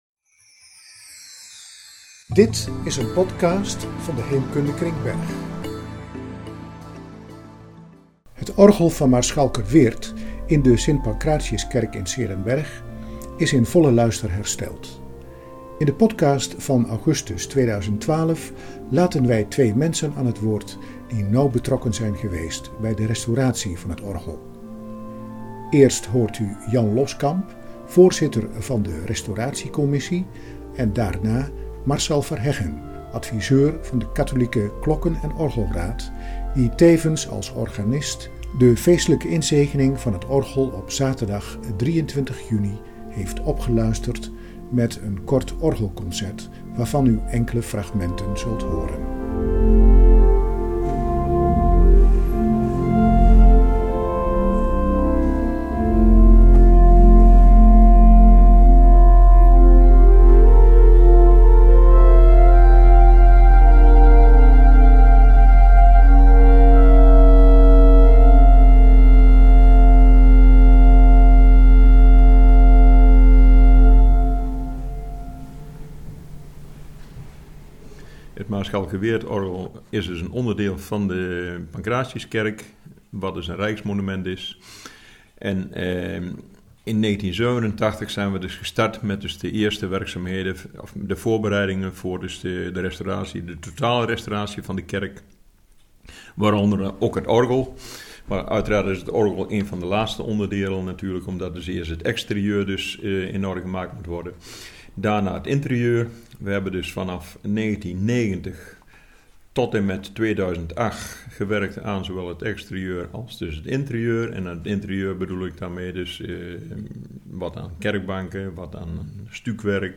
In de podcast van augustus 2012 laten we twee mensen aan het woord die nauw betrokken zijn geweest bij de restauratie van het orgel.
Het geluid van het orgel is met beperkte middelen opgenomen.